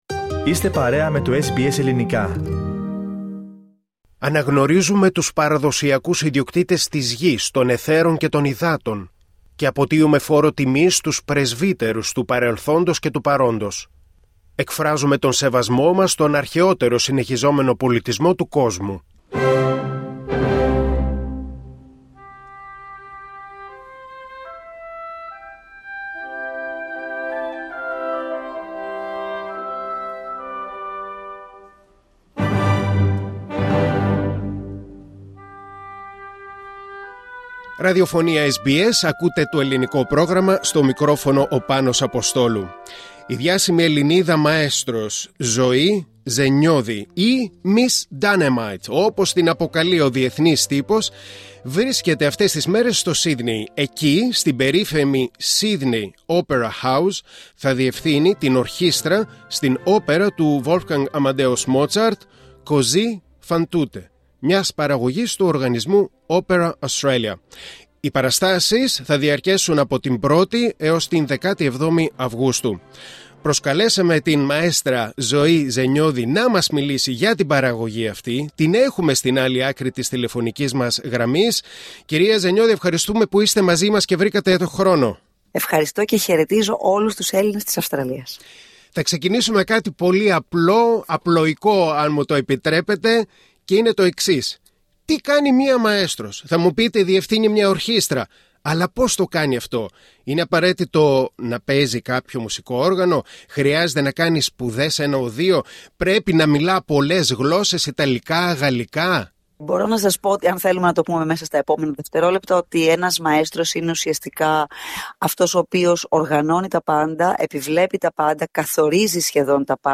Λίγο μετά τον ερχομό της στην Αυστραλία και λίγες μέρες πριν την πρεμιέρα της όπερας «Così fan tutte» του Βόλφγκανγκ Αμαντέους Μότσαρτ παραγωγής της Opera Australia μίλησε στο SBS Greek